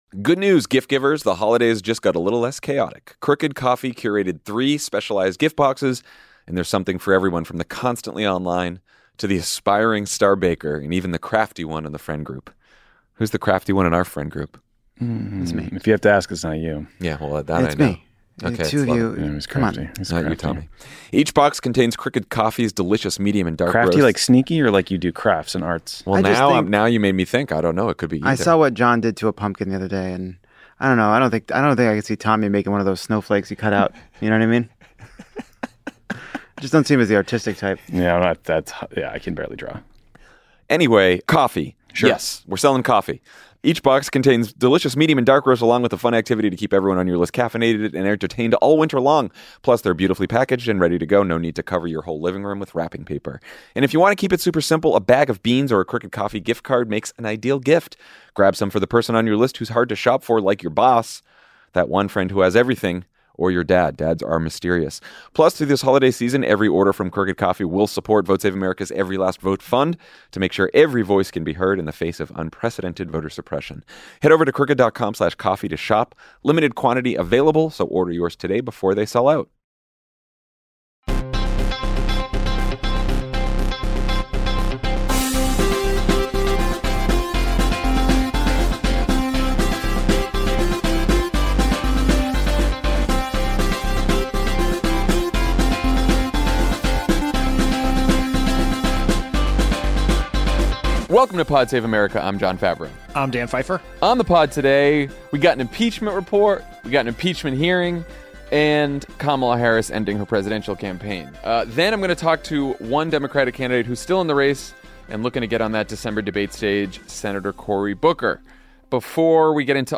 House Democrats prepare to draft articles of impeachment, Trump embarrasses himself and America at NATO, and Kamala Harris ends her presidential campaign. Then Senator Cory Booker talks to Jon about his strategy to make the December debate and build momentum ahead of Iowa.